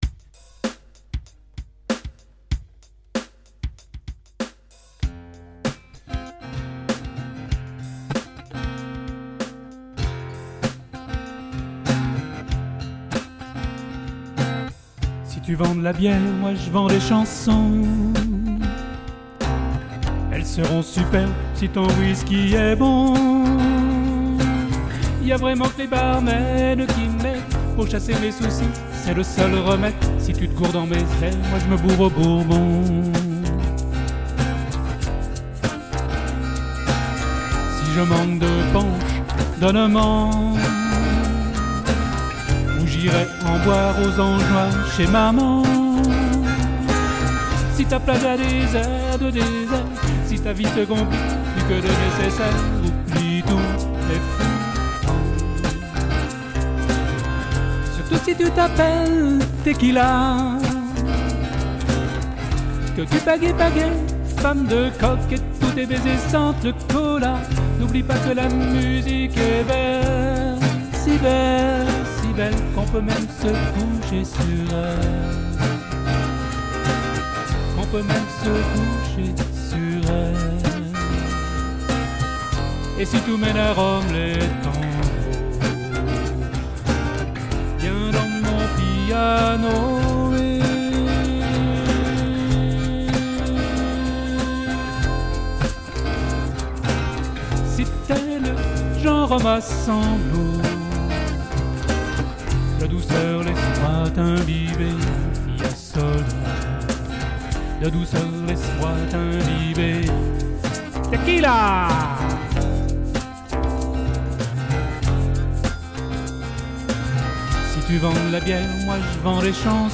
Une reprise d’une chanson